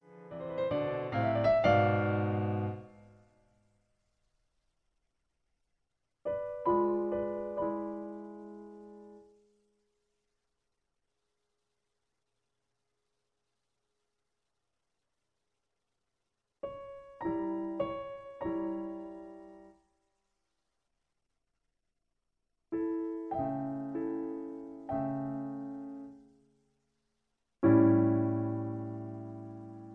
Recitative and aria. Original Key. Faster tempo.